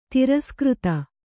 ♪ tiraskřta